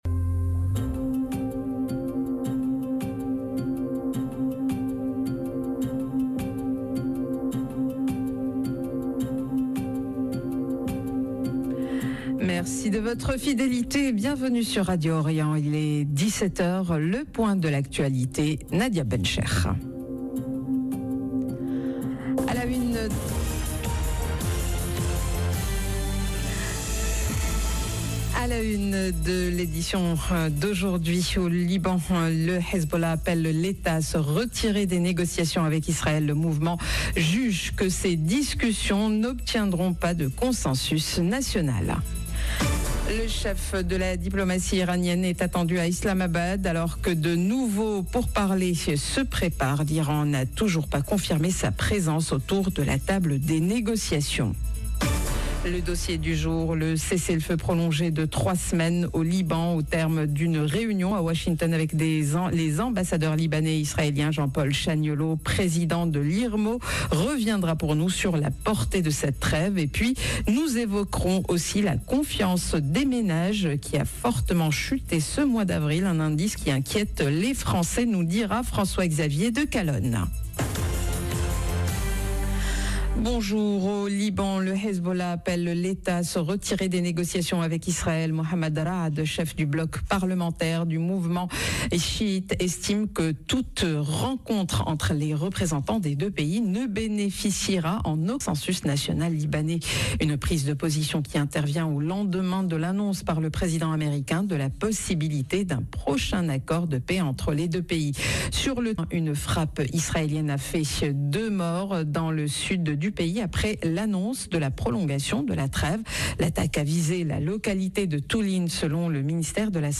Journal de 17H